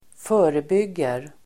Uttal: [²f'ö:rebyg:er]